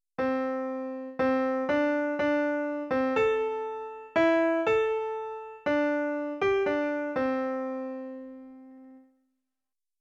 Creating a pentatonic melody
pentatonicmelody.wav